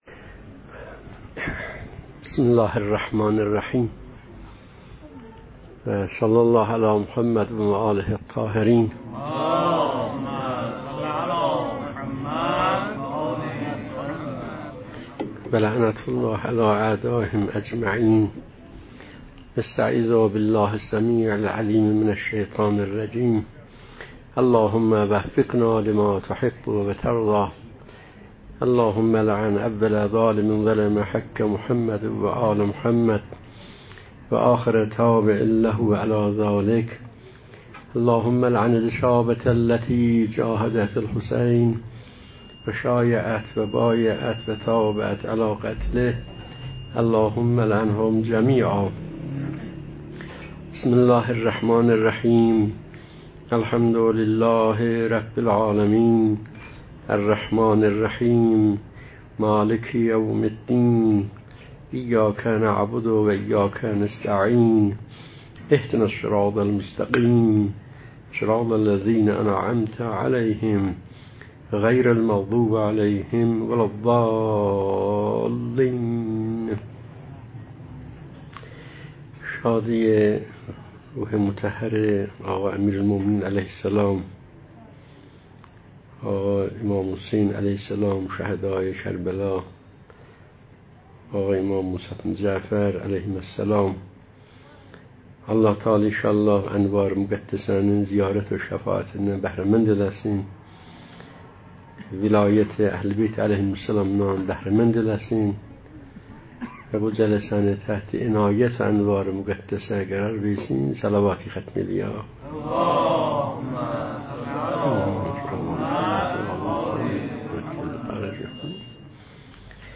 🌎مکان: میانه-حسینیه ثارالله